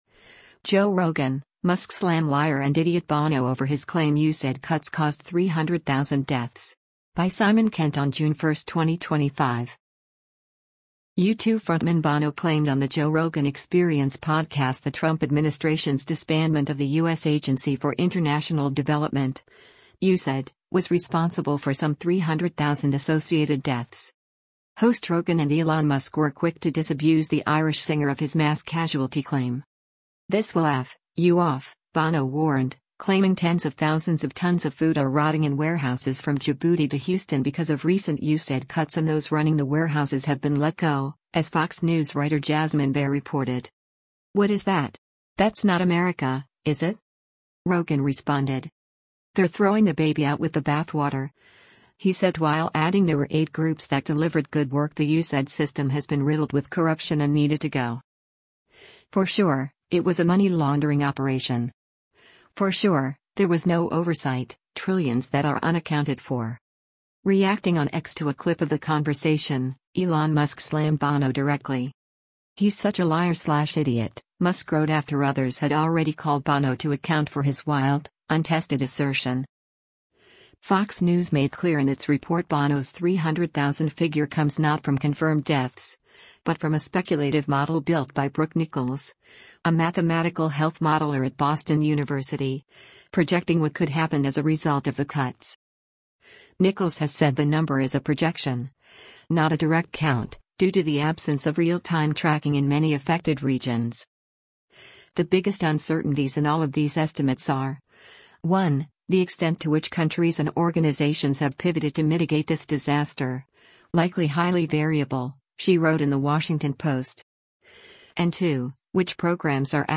U2 frontman Bono claimed on “The Joe Rogan Experience” podcast the Trump administration’s disbandment of the U.S. Agency for International Development (USAID) was responsible for some 300,000 associated deaths.